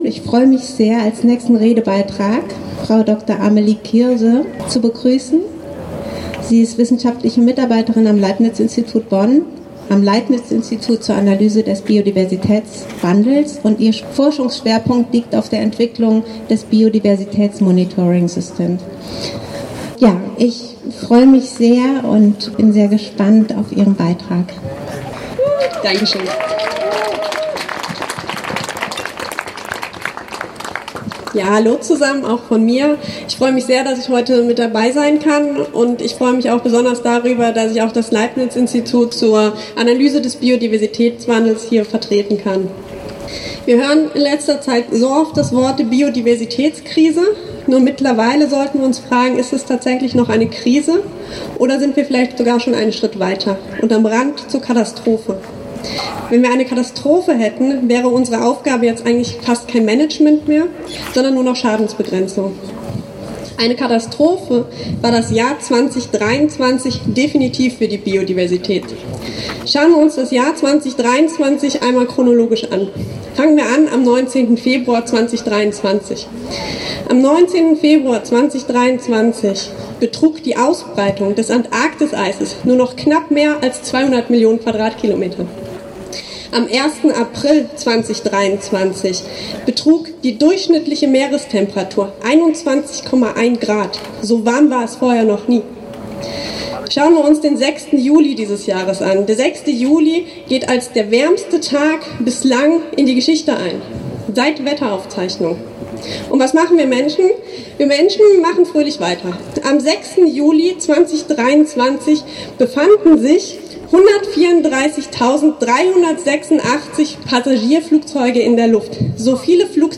Abschlusskundgebung
Die Ansprache